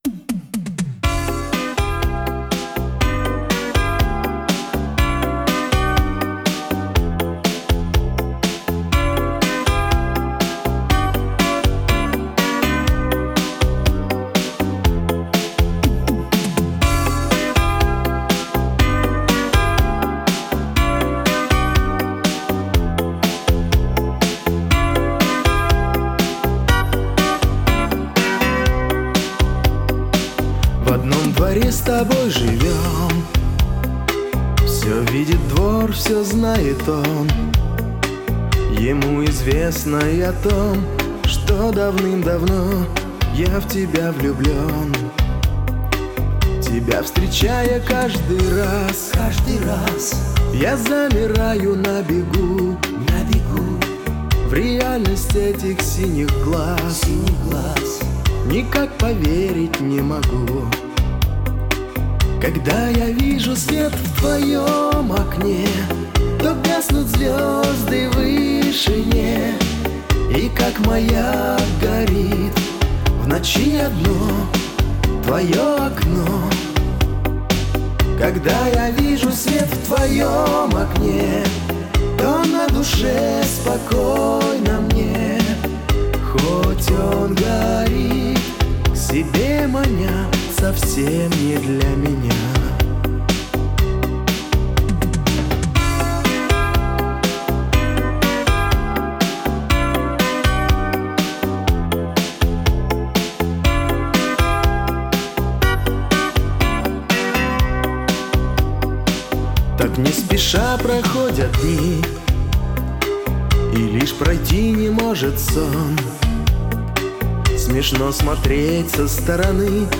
Жанр: Попса